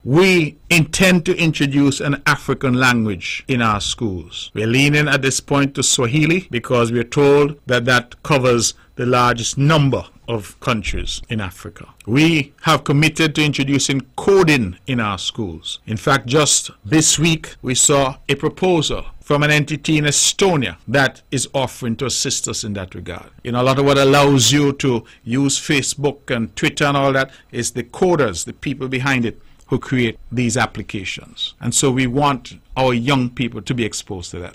During Wednesday’s edition of the “On the Mark” Program on VON Radio, Premier of Nevis, Hon. Mark Brantley spoke on a number of initiatives intended to be launched by the Nevis Island Administration (NIA).